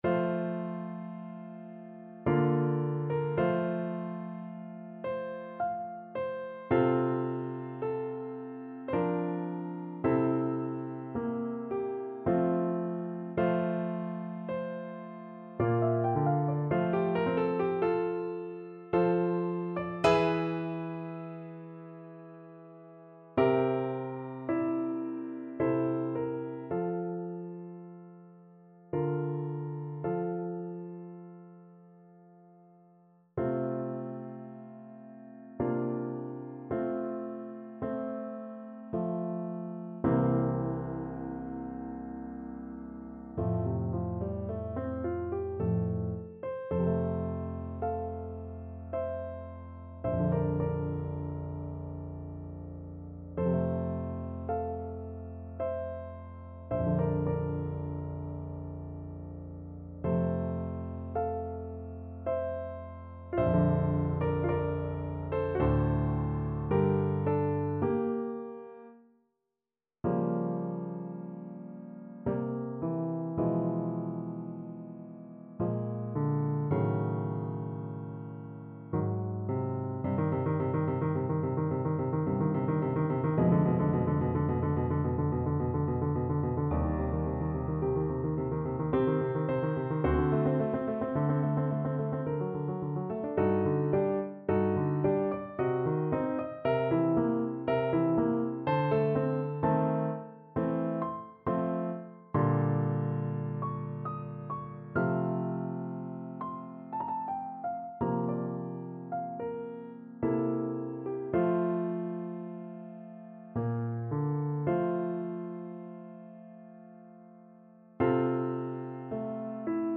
F major (Sounding Pitch) G major (Clarinet in Bb) (View more F major Music for Clarinet )
3/4 (View more 3/4 Music)
Adagio ma non troppo =108
Classical (View more Classical Clarinet Music)